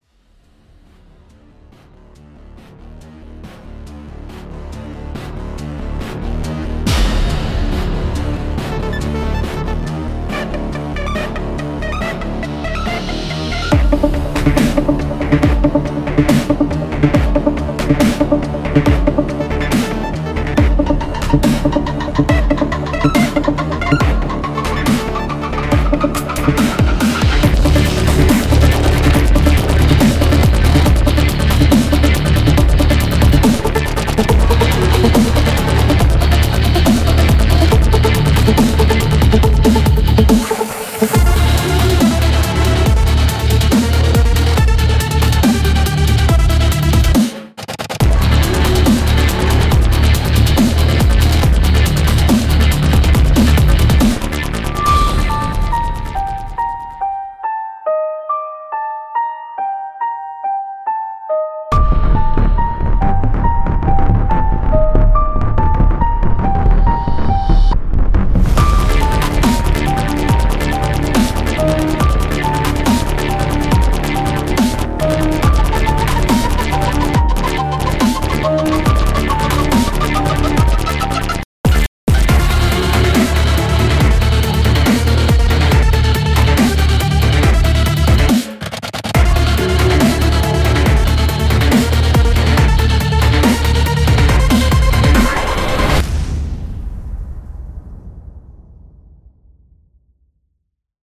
Each patch is crafted for immediate impact and maximum tweakability, delivering cinematic leads, distorted sequences, brutal basslines, shimmering pads, synthetic risers, and high-intensity plucks.
• Categorized into: Arps, Sequences, Gates and FXs
• * The video and audio demos contain presets played from Overclock Gate sound bank, every single sound is created from scratch with Dune 3.
• * All sounds of video and audio demos are from Overclock Gate (except drums, bass and additional arrangements).